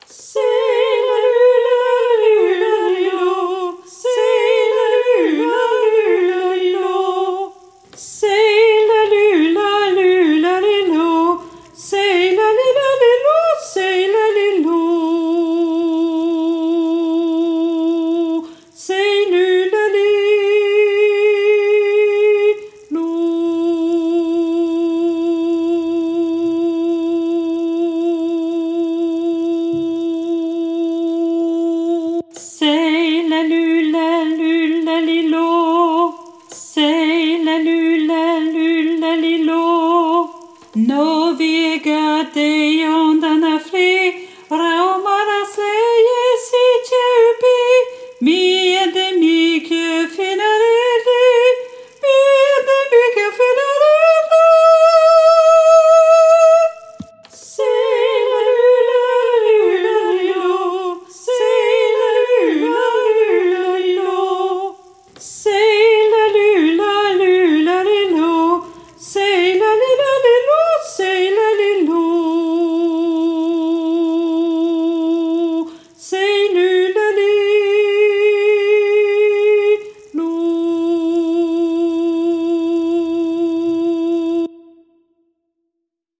Alto 2